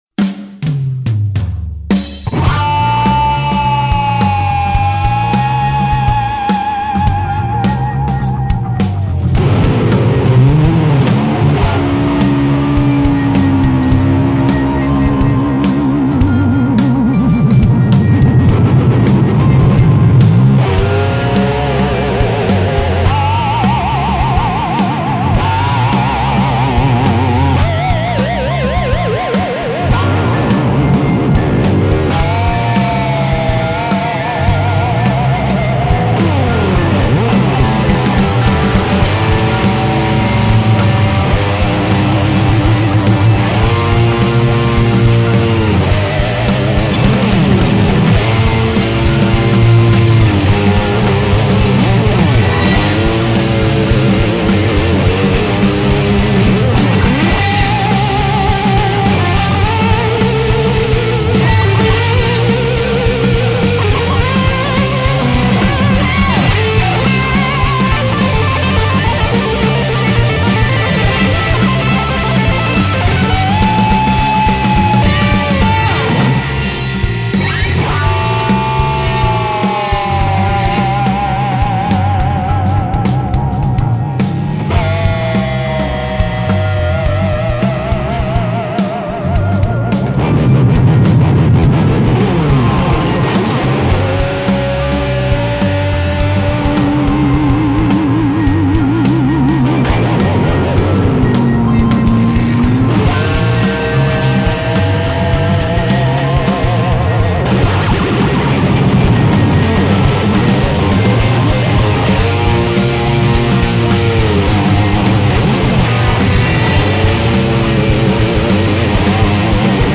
Hier ist mein Beitrag zum aktuellen Backing:
Das war die Maxxas mit dem Demonizer.